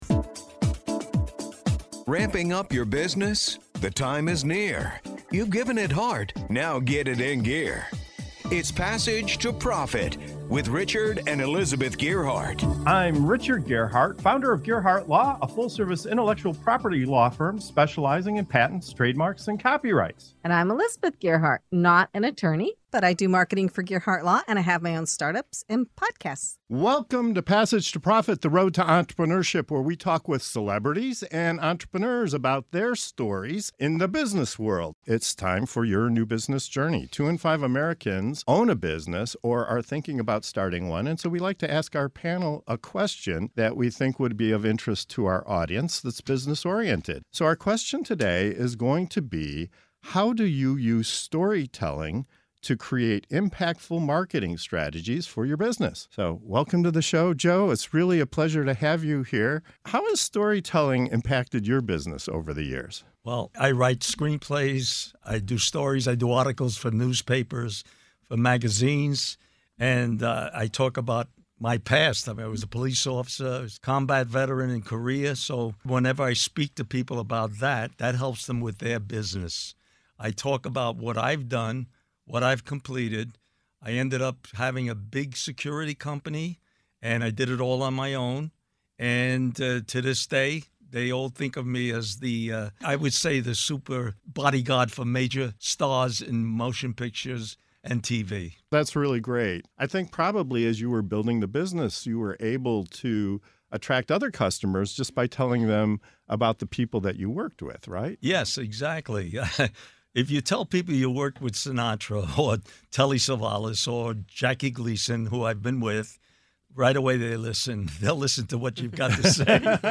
This segment of “Your New Business Journey” on Passage to Profit Show dives into the power of storytelling in business, featuring guests who’ve mastered the art of captivating audiences. From Hollywood’s elite to life-saving self-defense training, our panel shares how personal stories can build brands, inspire trust, and create lasting impact.